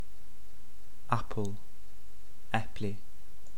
English word Icelandic word Spoken comparison